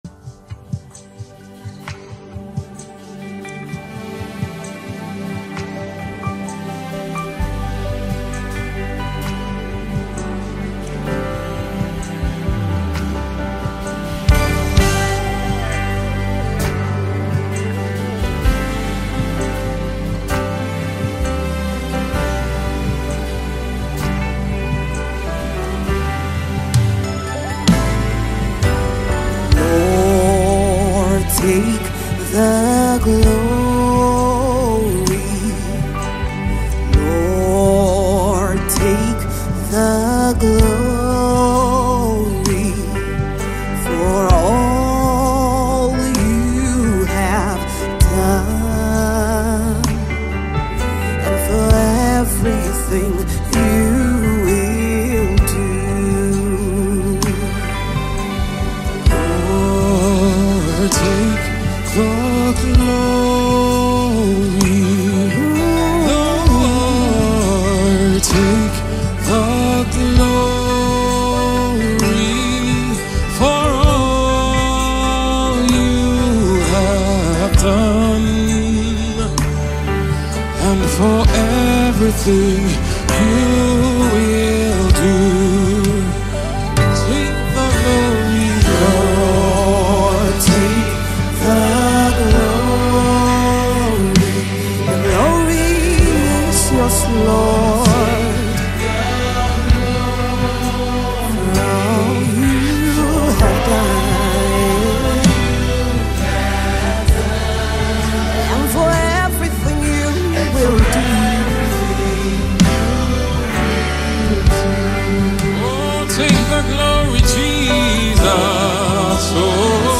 Mp3 Gospel Songs
captivating worship song